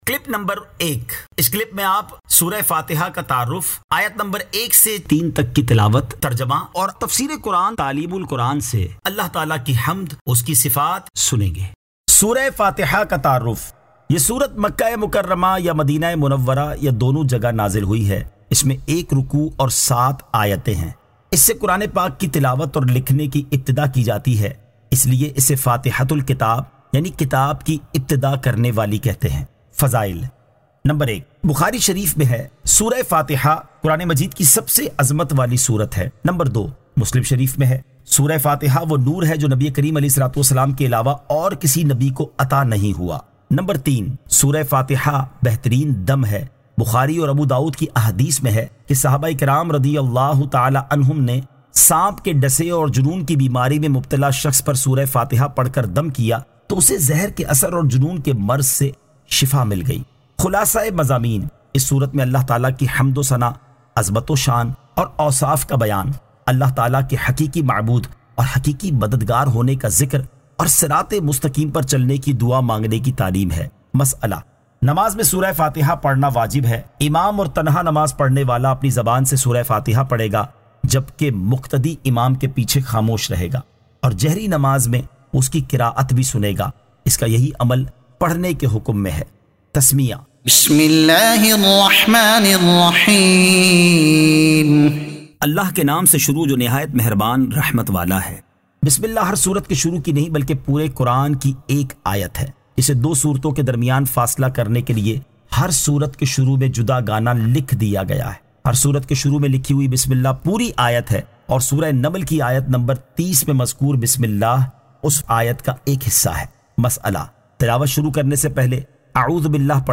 Surah Al-Fatiha Ayat 01 To 03 Tilawat , Tarjuma , Tafseer e Taleem ul Quran